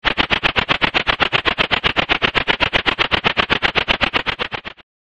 効果音 飛ぶ
ani_ge_tori_wing.mp3